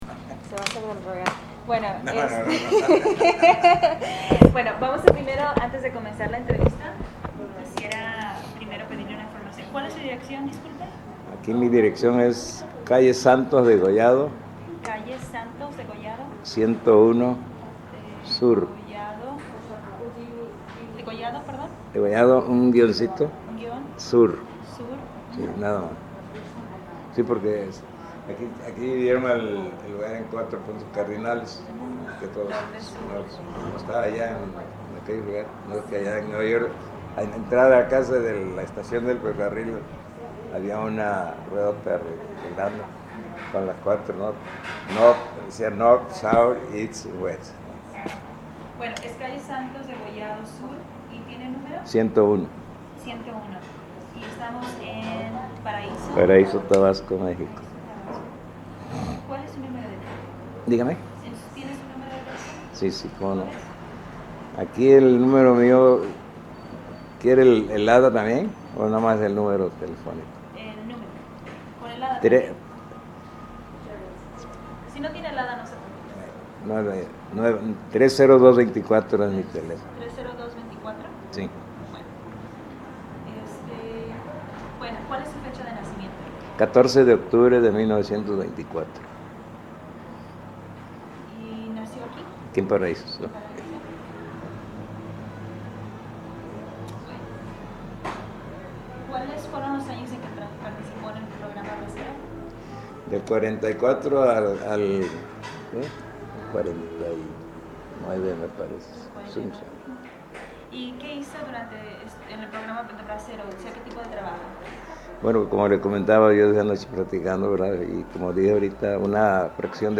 Location Paraiso, Tabasco Original Format Mini disc